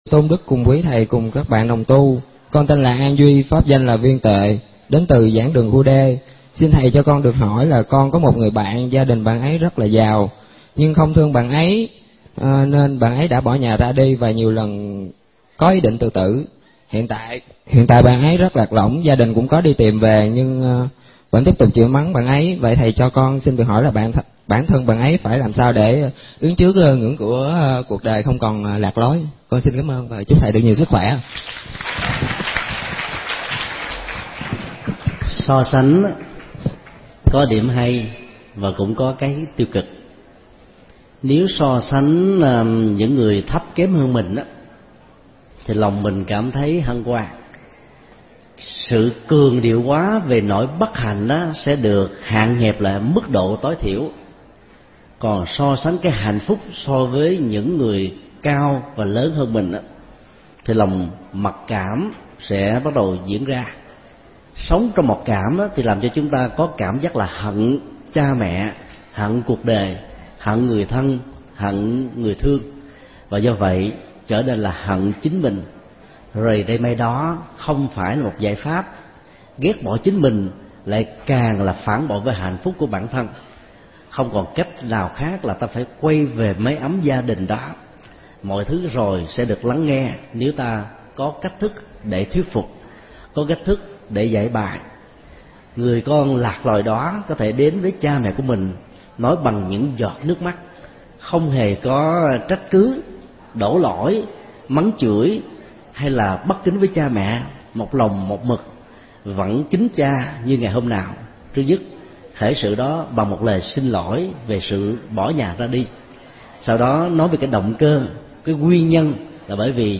Vấn đáp: Hướng dẫn khuyên bạn bị lạc lối sống – Thầy Thích Nhật Từ